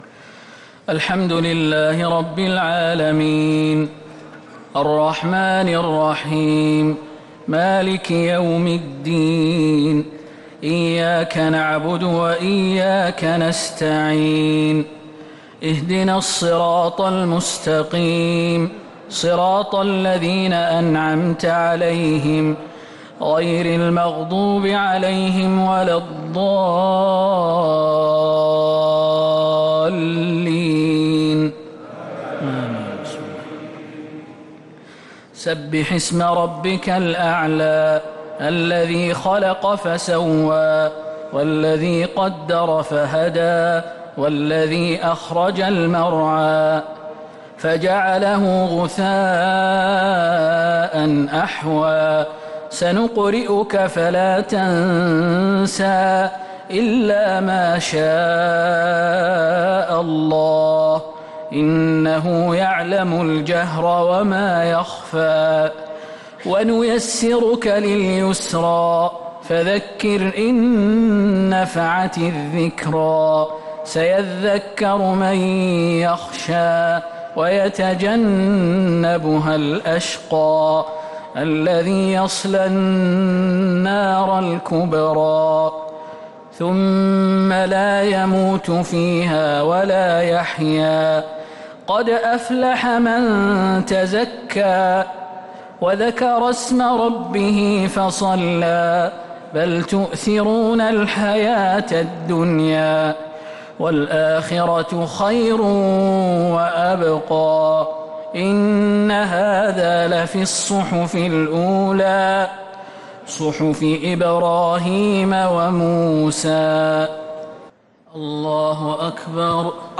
الشفع والوتر ليلة 19 رمضان 1447هـ | Witr 19th night Ramadan 1447H > تراويح الحرم النبوي عام 1447 🕌 > التراويح - تلاوات الحرمين